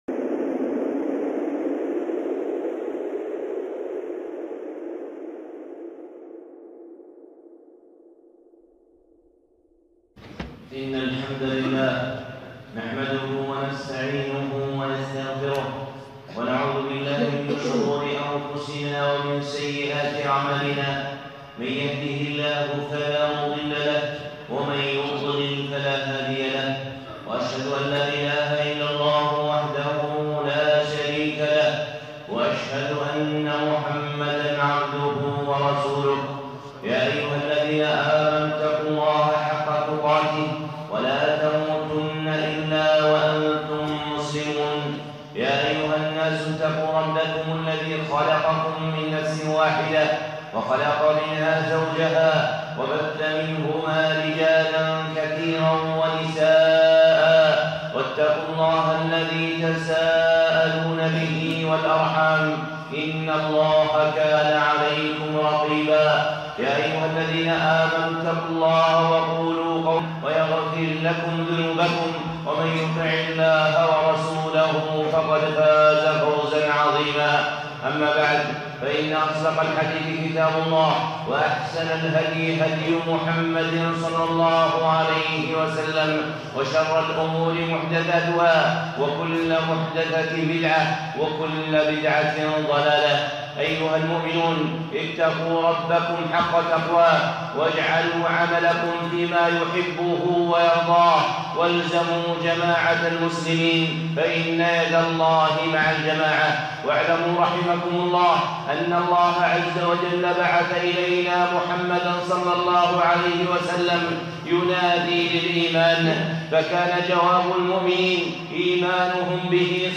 خطبة (أصول حلاوة الإيمان